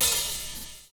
DISCO 16 OH.wav